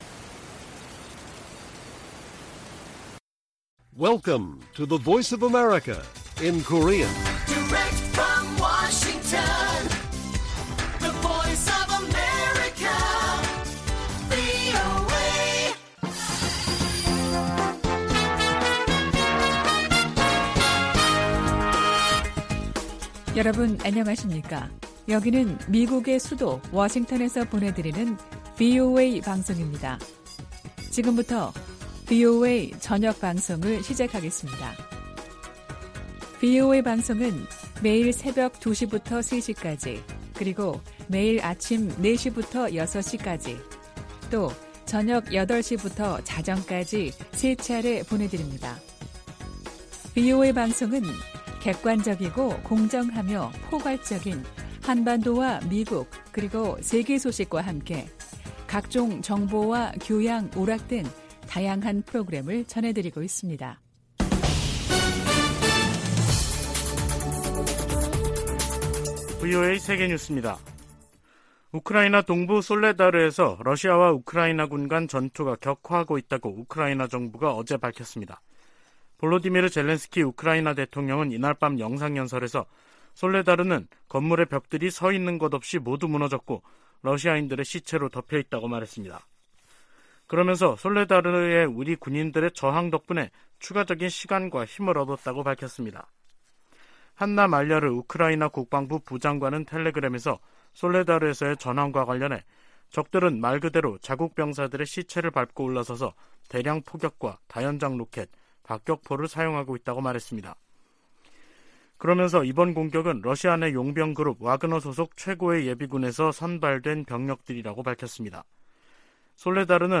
VOA 한국어 간판 뉴스 프로그램 '뉴스 투데이', 2023년 1월 10일 1부 방송입니다. 미 국무부는 방한 중인 국무부 경제 차관이 미국의 인플레이션 감축법(IRA)에 대한 한국의 우려에 관해 논의할 것이라고 밝혔습니다. 한국 군 당국은 대북 확성기 방송 재개 방안을 검토하고 있는 것으로 알려졌습니다.